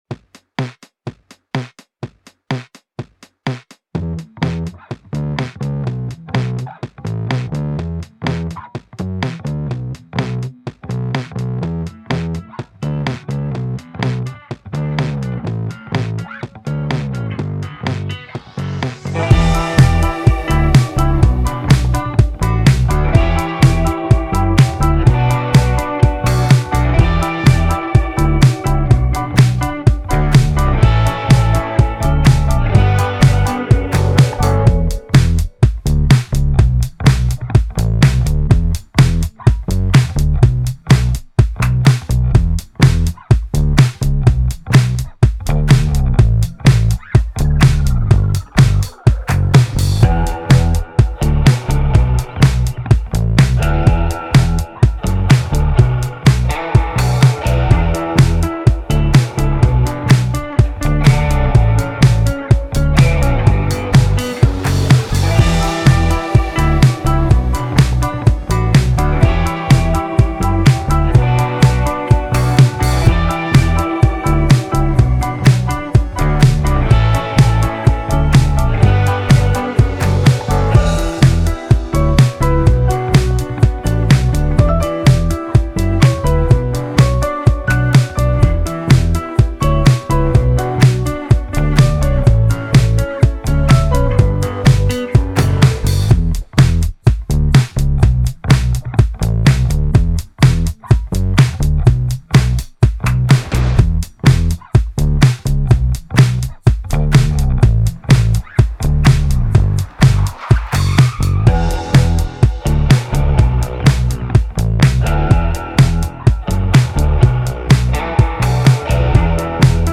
Pop Instrumentals